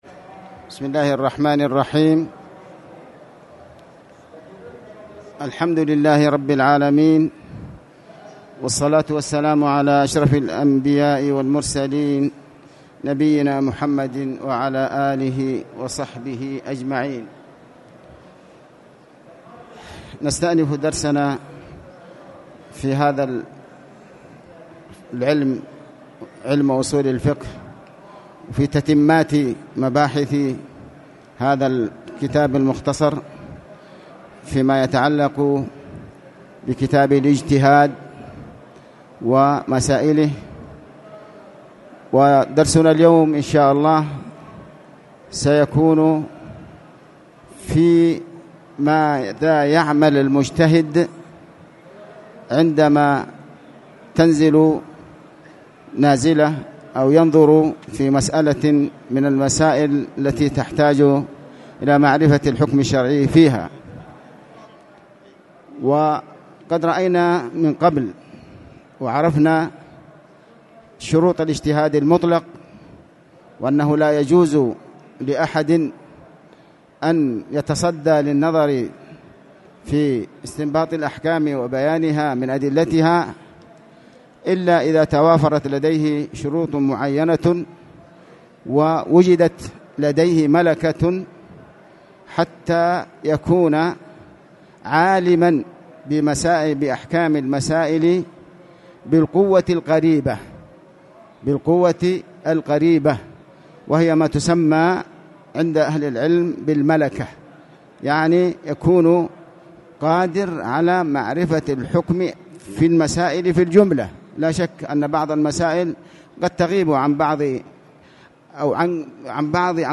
تاريخ النشر ٢٦ شوال ١٤٣٨ هـ المكان: المسجد الحرام الشيخ